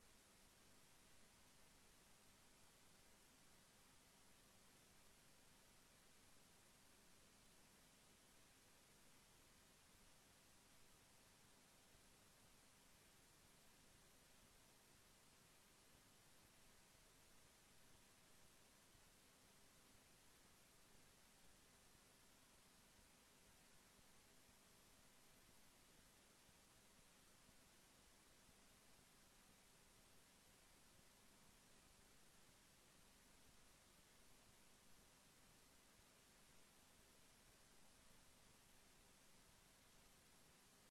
Gecombineerde commissievergadering Omgeving en Economie / Sociaal Domein en Bestuur 01 december 2025 19:30:00, Gemeente Dinkelland